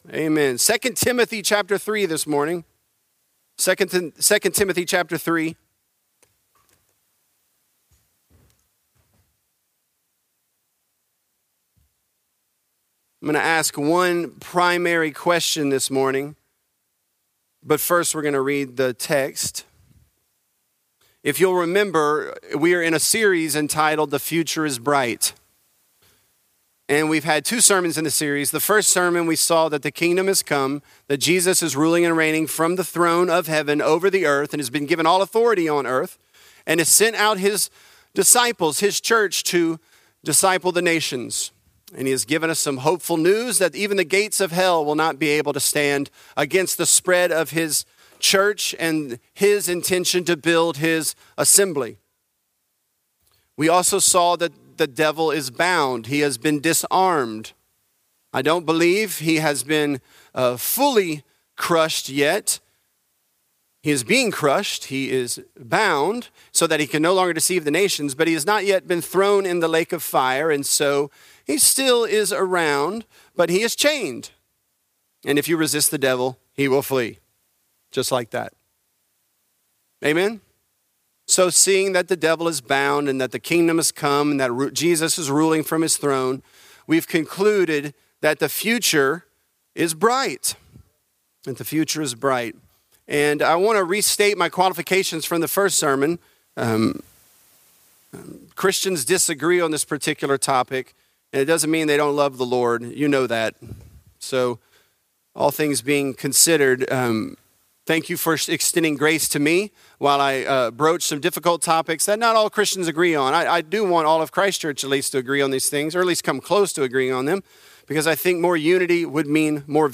The Kingdom Has Come: A Christian Philosophy Of History | Lafayette - Sermon (2 Timothy 3)